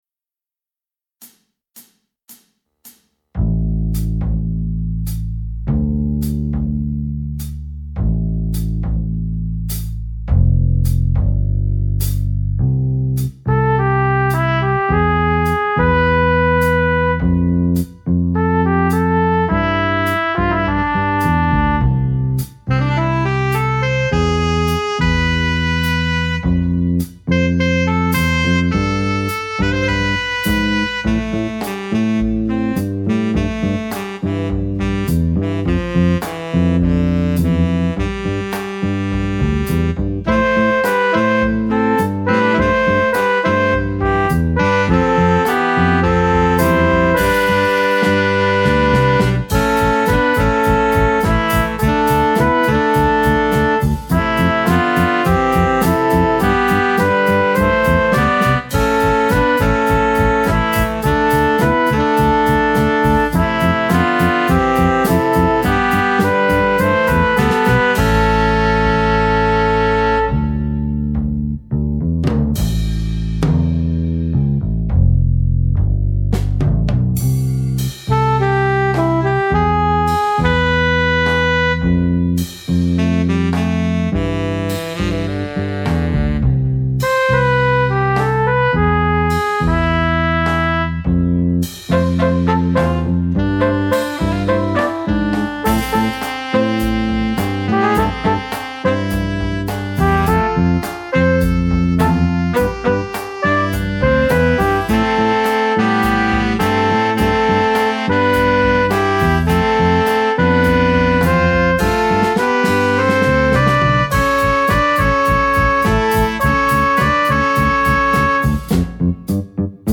minus Piano